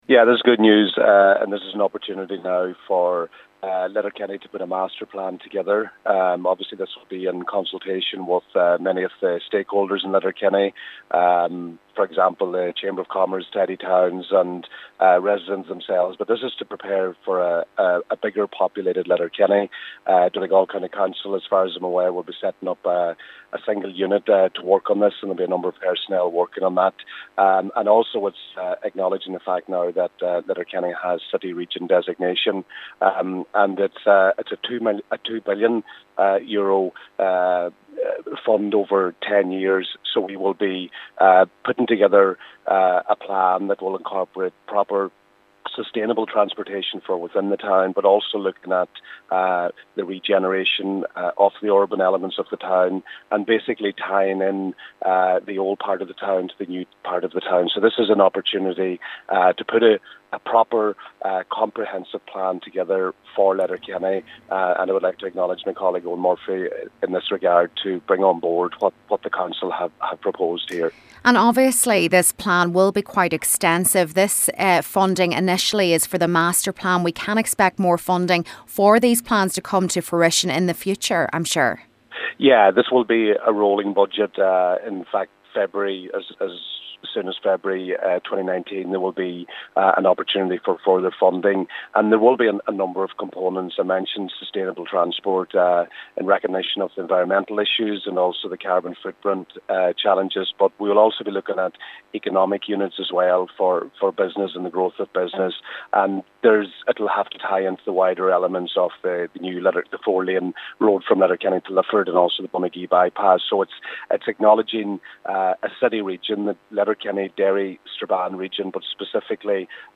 Donegal Minister Joe McHugh says more funding for the plan will be forthcoming but this is a good start: